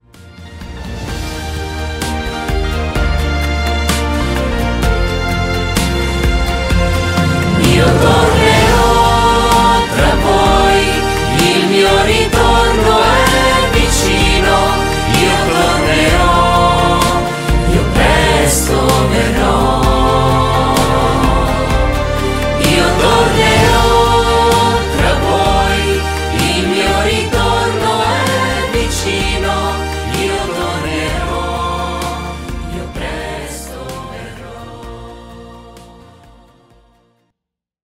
Canto per Rosario e Parola di Dio: Io tornerò (ritornello)